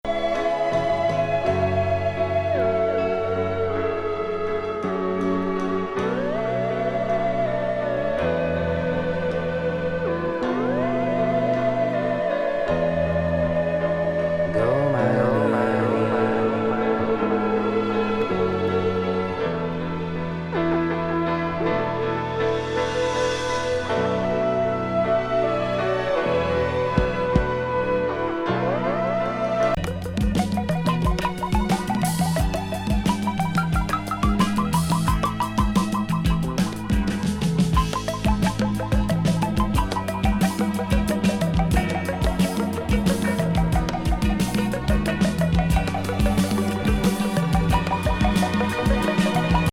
イタリア人コンポーザーによるドリーミー深海ARPなプログレッシブ？？